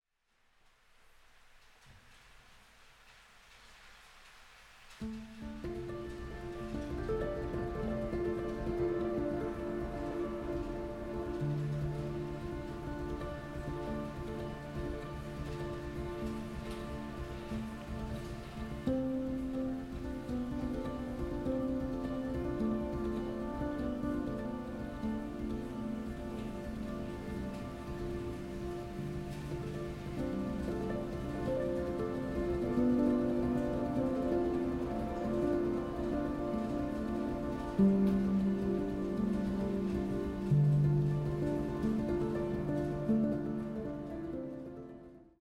ポスト・クラシカル
一歩引いたところから自分を、世界を見る、静かな熱量。